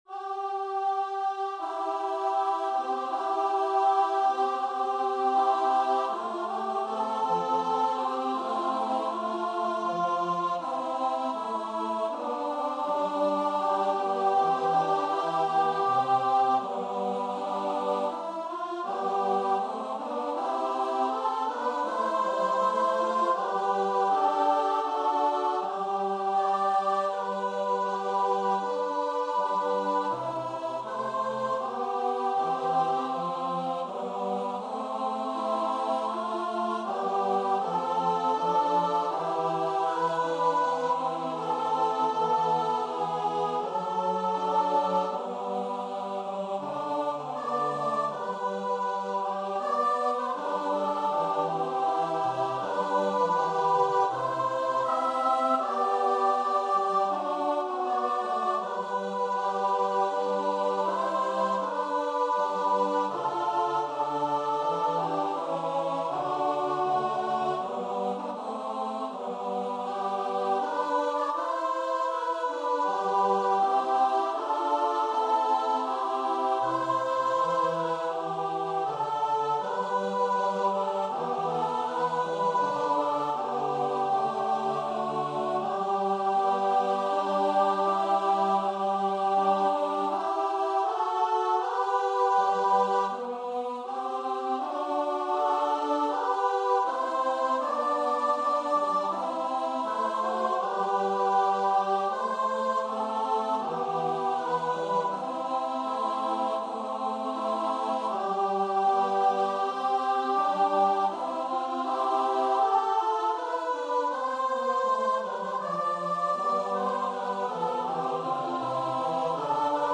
Free Sheet music for Choir (SATB)
4/2 (View more 4/2 Music)
C major (Sounding Pitch) (View more C major Music for Choir )
Choir  (View more Intermediate Choir Music)
Classical (View more Classical Choir Music)